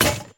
break.ogg